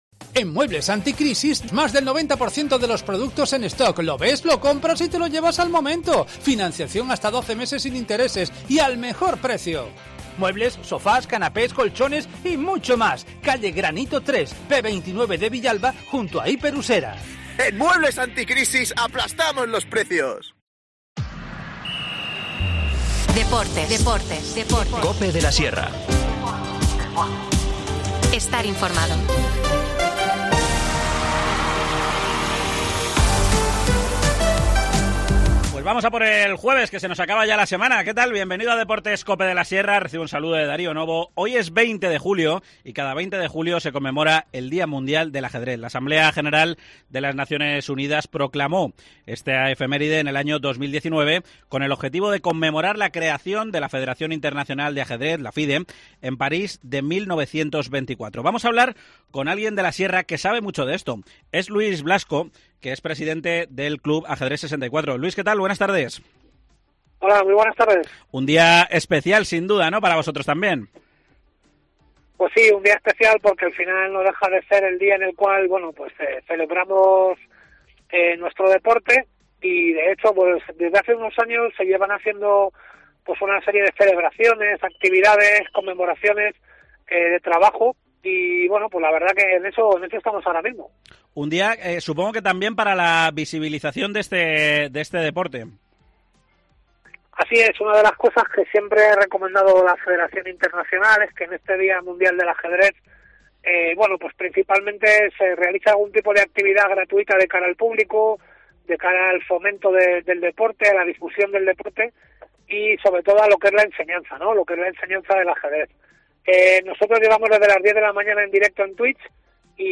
Deportes local